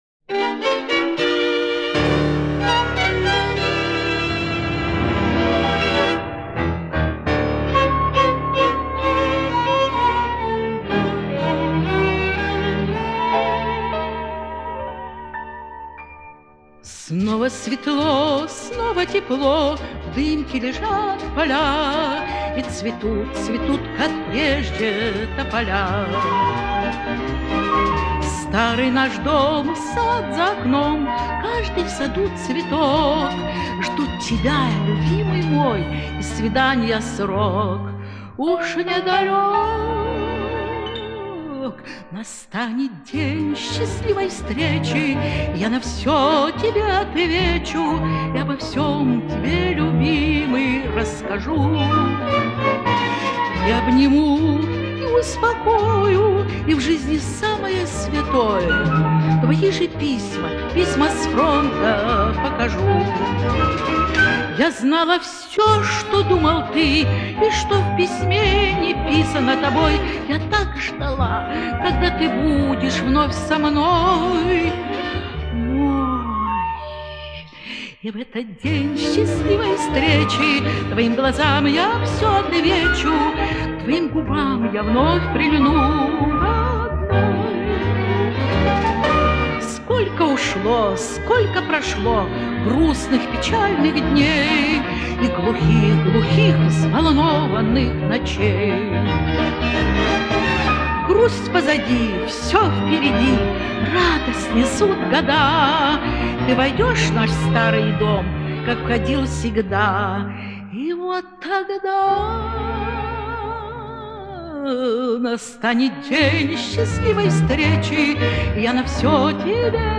Её исполнению присуща глубокая задушевность.